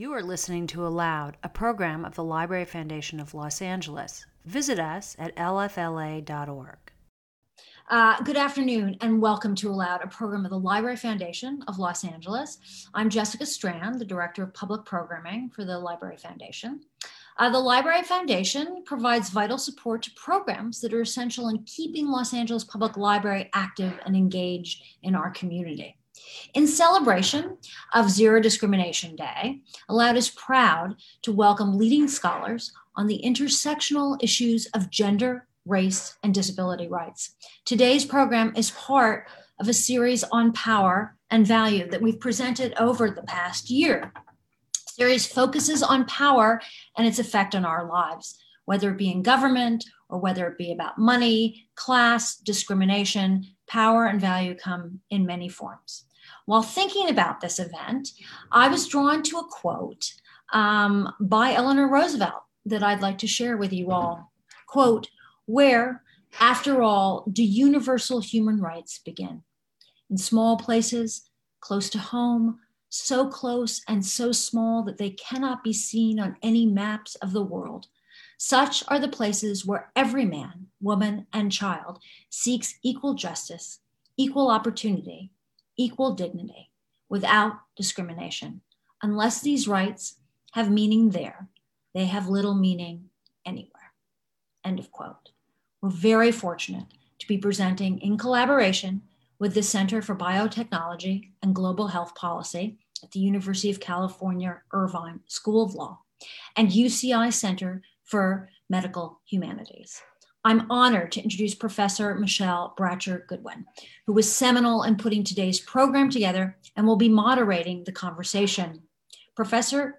As our fractured country moves forward after a year of social unrest and political division—how can we work towards inclusion, equity, and real change in our society? In celebration of Zero Discrimination Day, ALOUD is proud to welcome leading activists and academics for a discussion of the intersectional issues of gender, race, and disability rights.